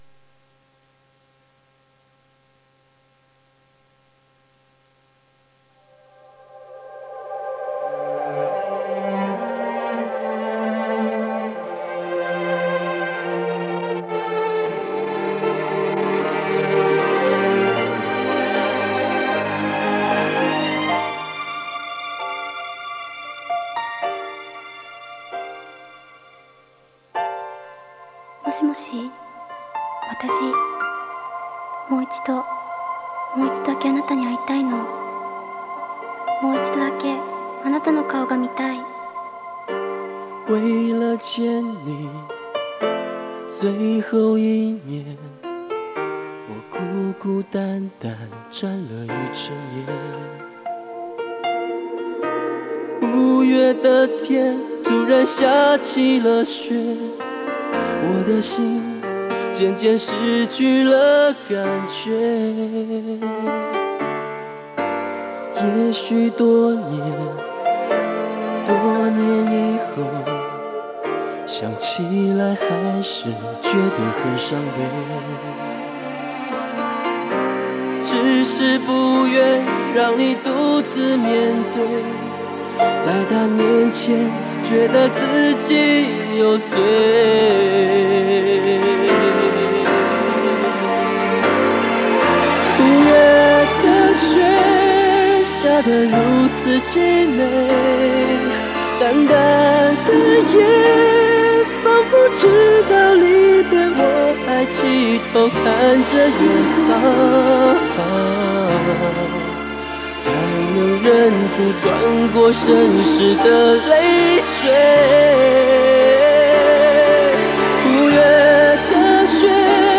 磁带数字化：2022-08-02